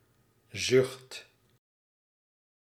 Ääntäminen
IPA : /saɪ/